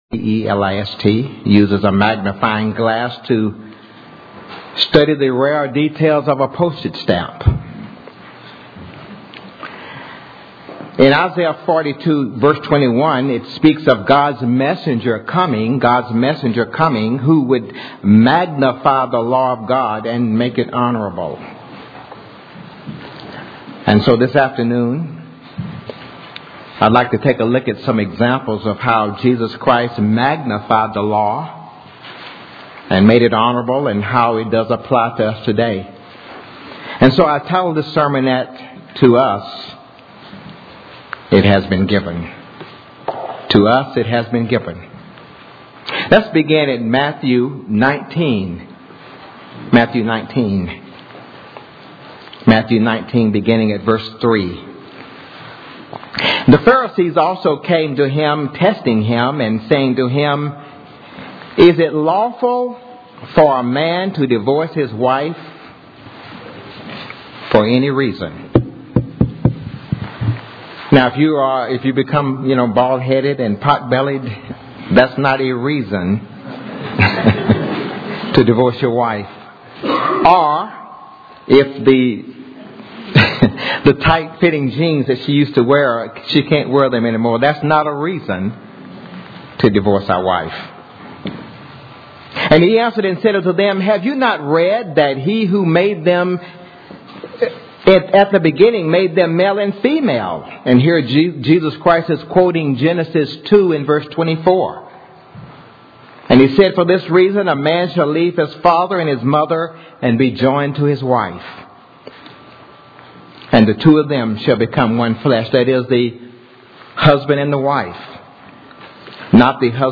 This split sermon gives many examples of how Jesus Christ, rather than doing away with the Law of God, magnified its meaning and spiritual application. For example, He showed that not only is it a sin to commit physical murder, but it is sin to harbor thoughts of hatred toward another person.